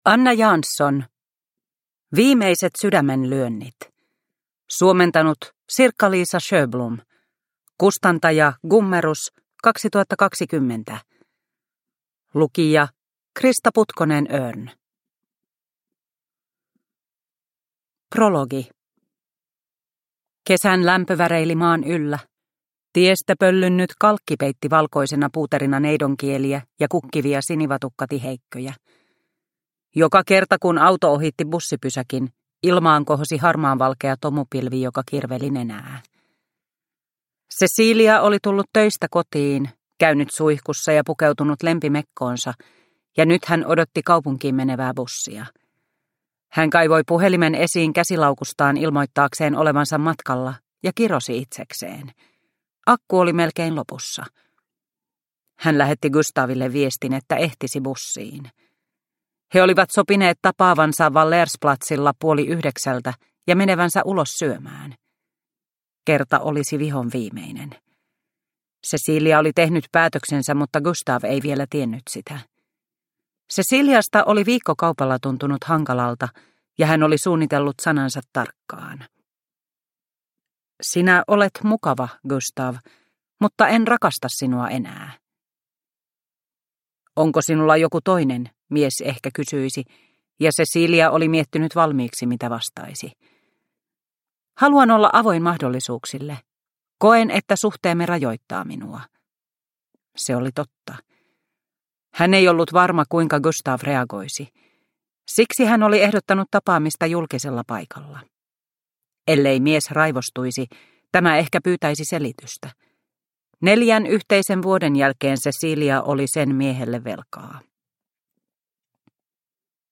Viimeiset sydämenlyönnit – Ljudbok – Laddas ner